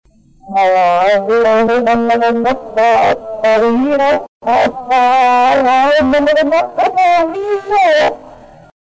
E la tristezza che lega le sue parole è davvero grande, e lei non riesce a nasconderla nemmeno dietro il tono "ruffiano" che usa per comunicare con noi.